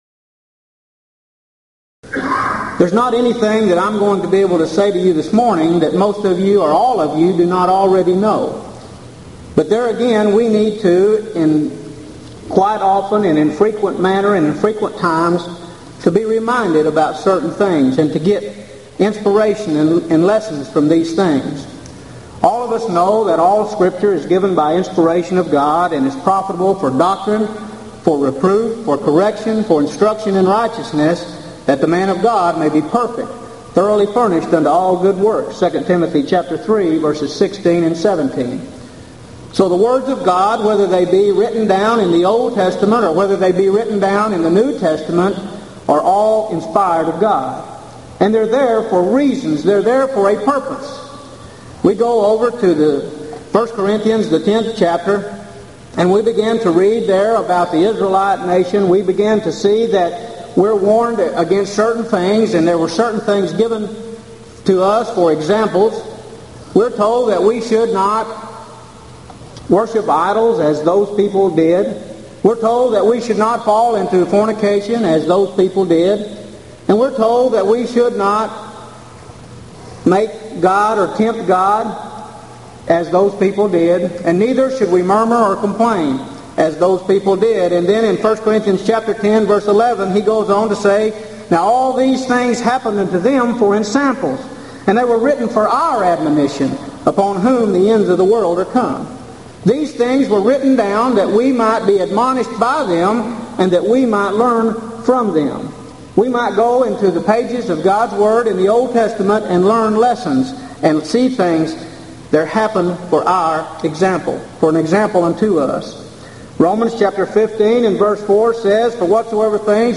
Event: 1995 Gulf Coast Lectures Theme/Title: Answering Alleged Contradictions & Problems In The Old Testament
lecture